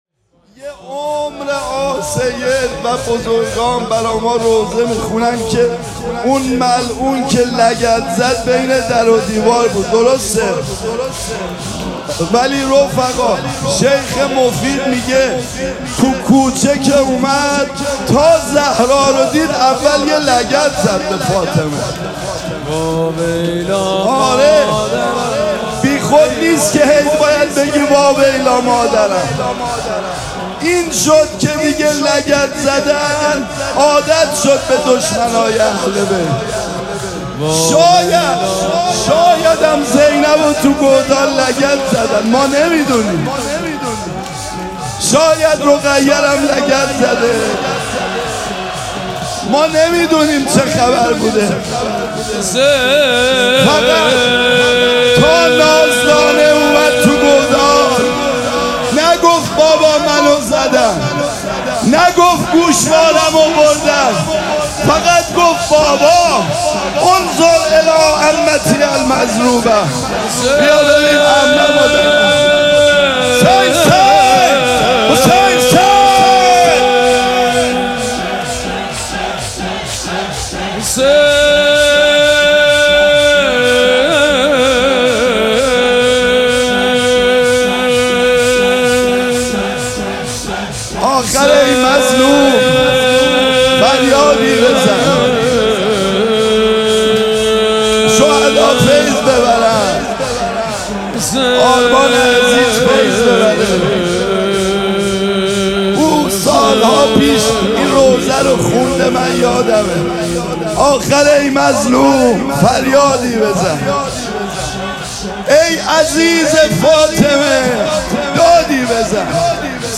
شب اول مراسم عزاداری دهه دوم فاطمیه ۱۴۴۶
حسینیه ریحانه الحسین سلام الله علیها
شور
مداح